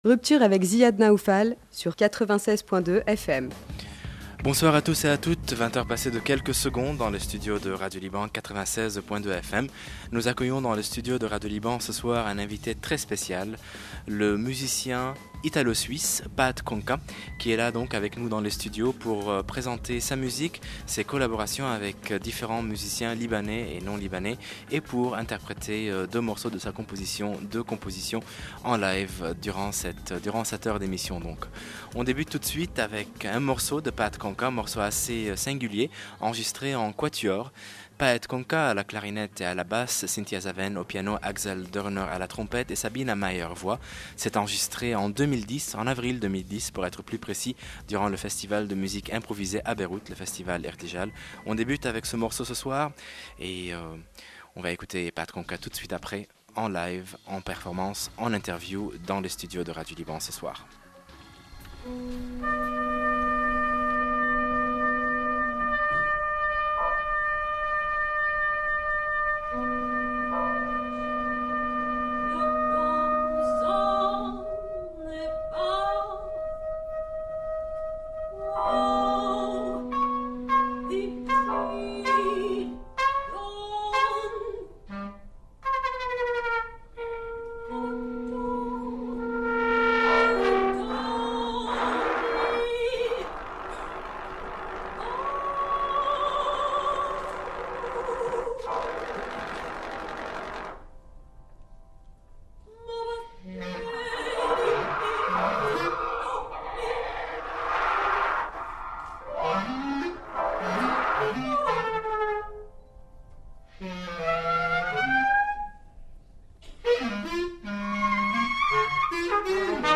interview + exclusive live performance